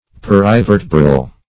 Perivertebral \Per`i*ver"te*bral\, a.